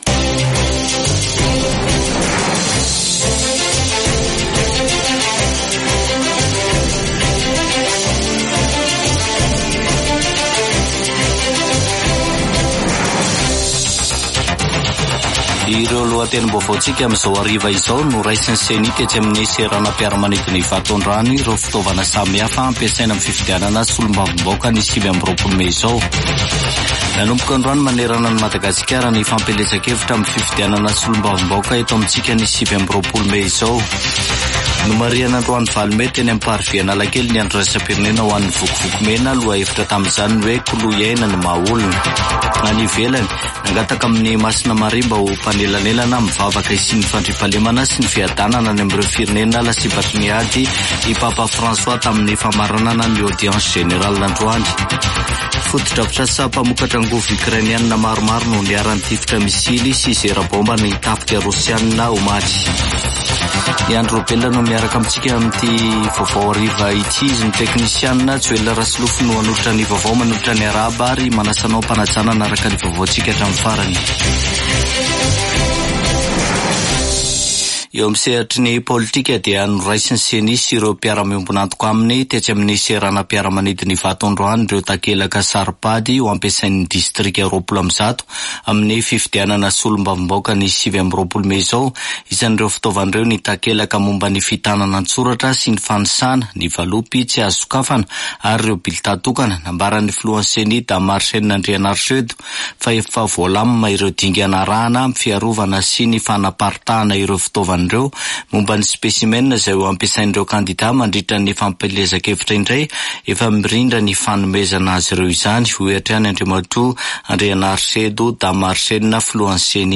[Vaovao hariva] Alarobia 8 mey 2024